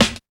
134 SNARE 2.wav